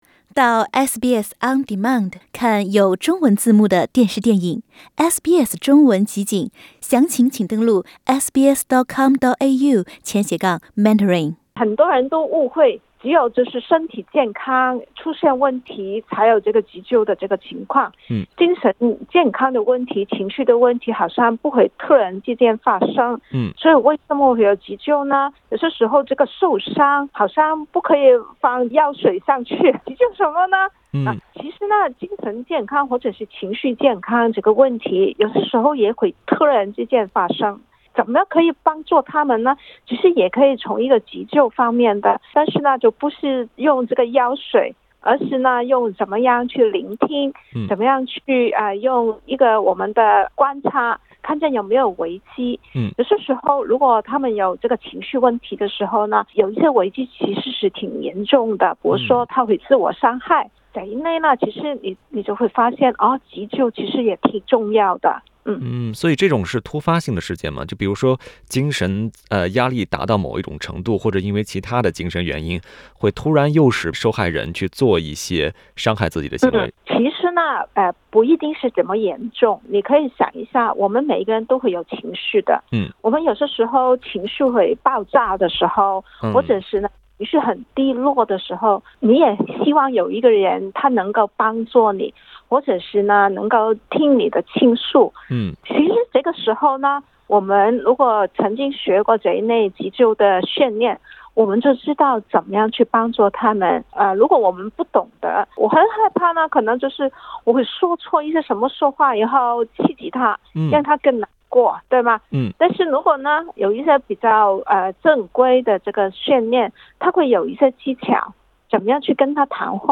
SBS 普通話電台